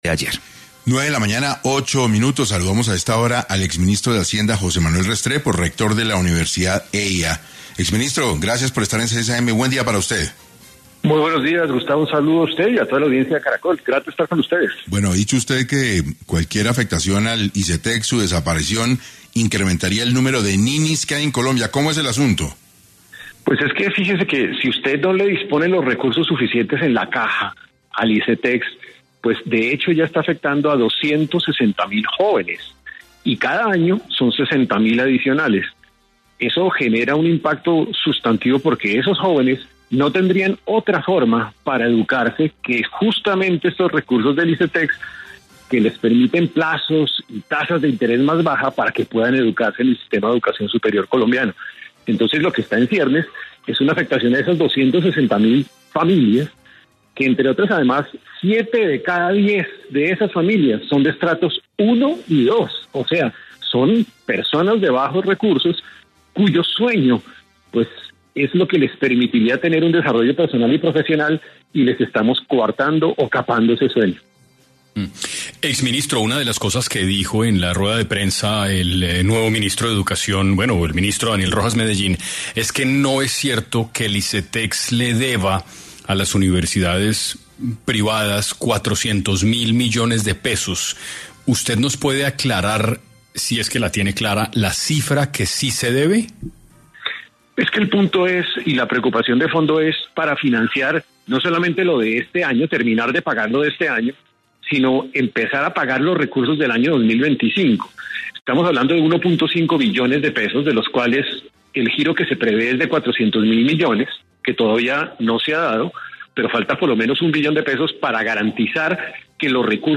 En 6AM de Caracol Radio, habló José Manuel Restrepo, quien mostró su preocupación por la crisis que sufre la entidad financiera que otorga créditos educativos para la realización de estudios superiores.